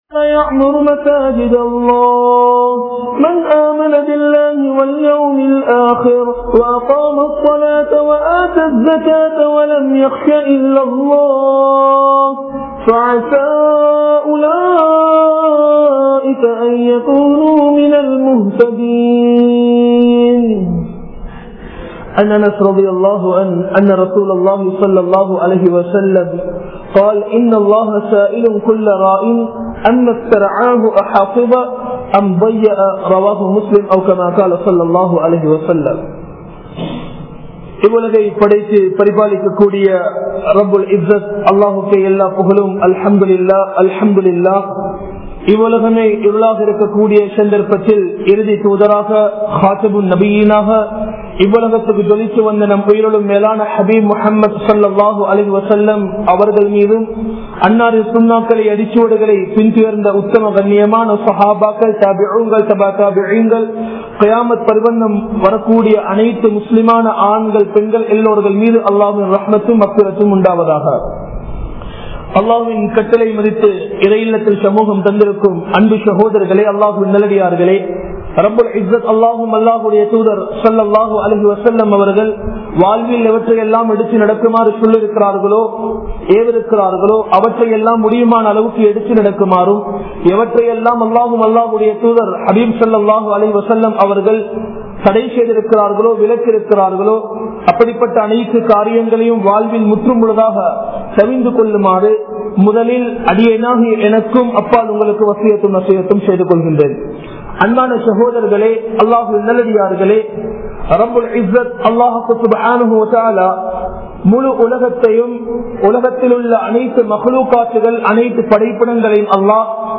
Masjidhum Athan Niruvaahihalum (மஸ்ஜிதும் அதன் நிருவாகிகளும்) | Audio Bayans | All Ceylon Muslim Youth Community | Addalaichenai
Warasamull Jumma Masjidh